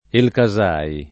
Elcasai [ elka @# i ] → Elxai